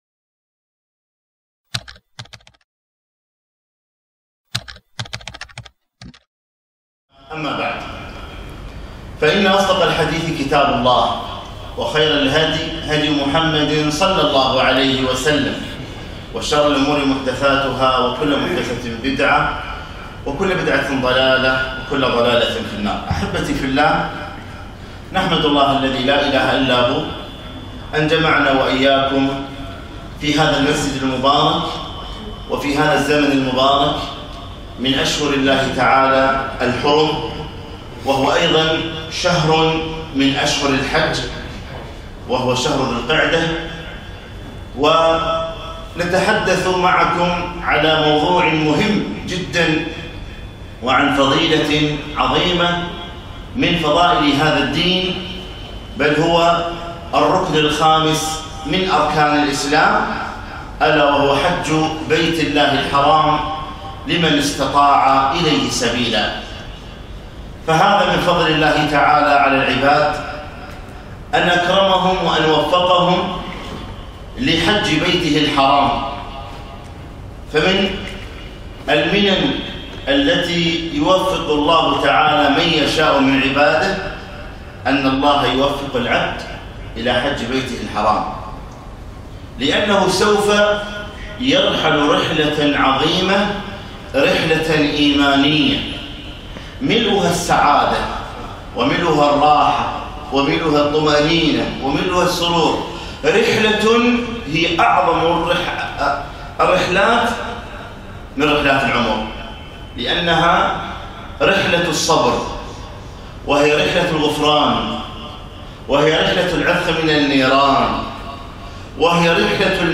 محاضرة - فضـائل الـحج - دروس الكويت